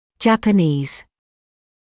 [ˌʤæpə’niːz][ˌджэпэ’ниːз]японский